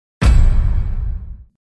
重音转场.mp3